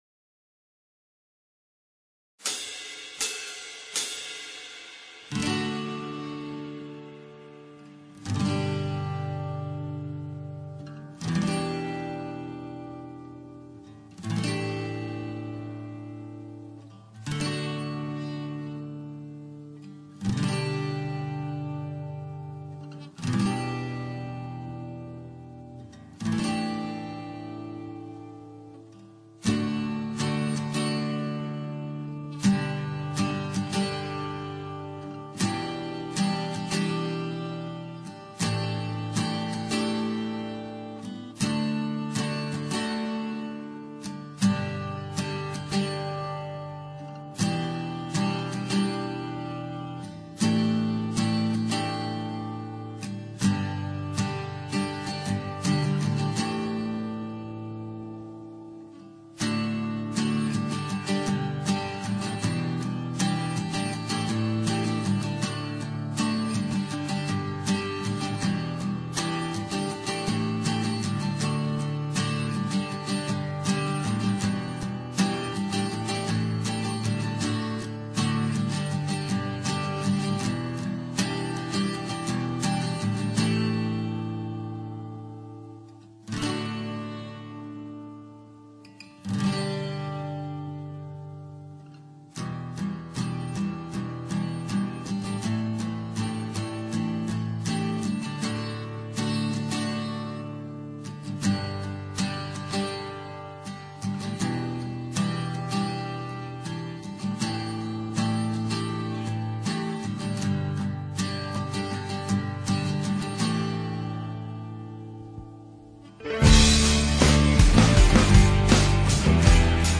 BASE INSTRUMENTAL